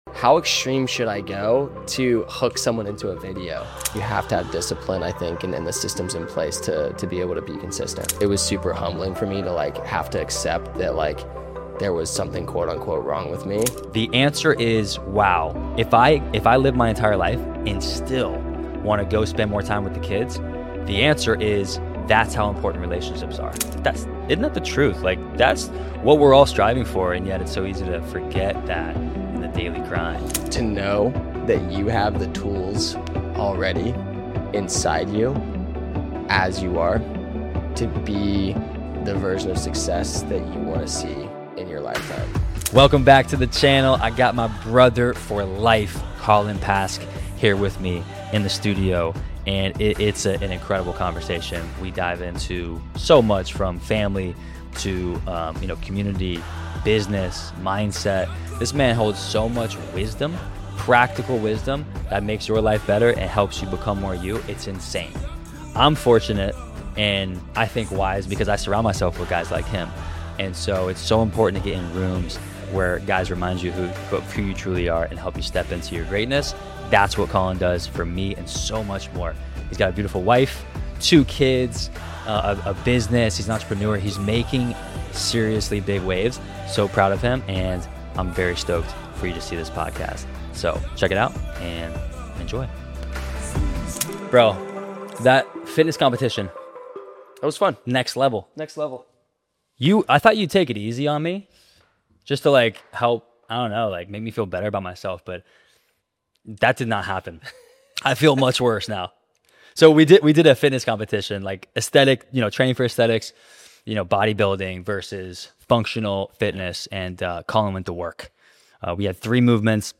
Whether you're a new father, a busy entrepreneur, or someone striving to stay aligned with your values while still showing up physically, mentally, and emotionally.. this conversation will hit home.